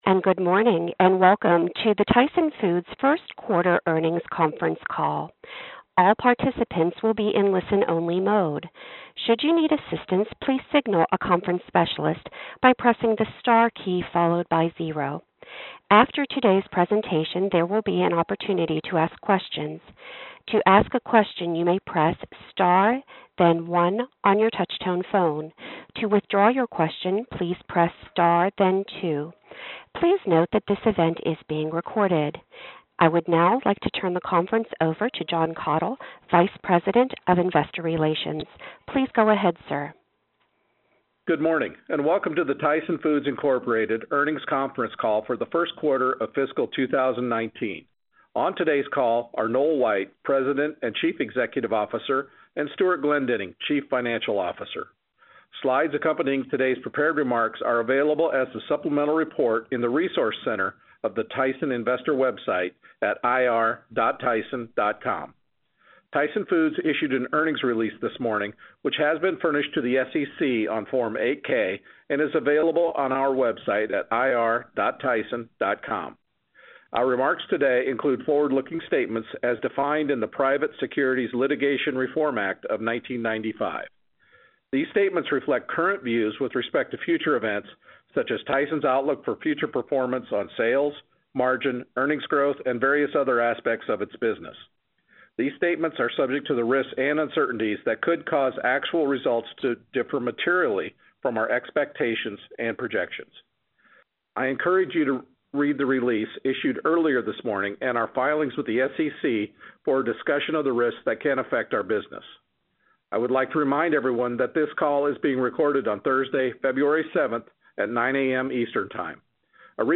Tyson Foods Inc. - Q1 2019 Tyson Foods Earnings Conference Call